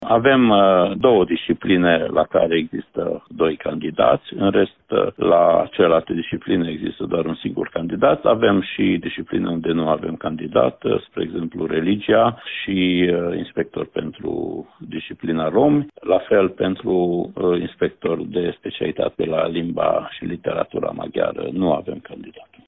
Inspectorul școlar general al județului Mureș, Ștefan Someșan, a explicat pentru RTM că au fost specialități cu mai mulți candidați pe un loc, dar și posturi pentru care nu au fost persoane interesate.